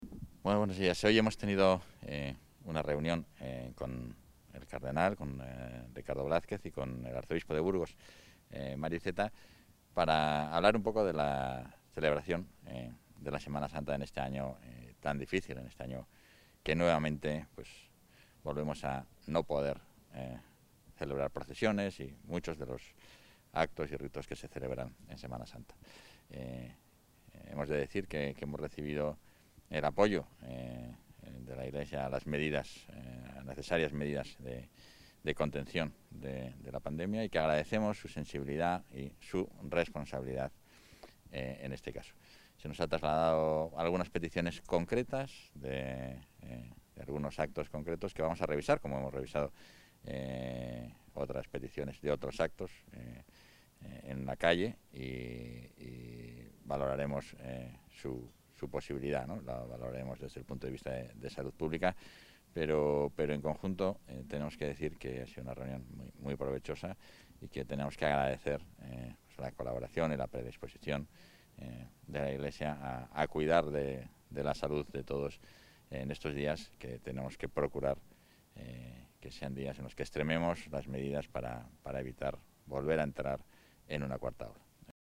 Declaraciones del vicepresidente Igea tras su reunión con monseñor Ricardo Blázquez
Valoración del vicepresidente y portavoz.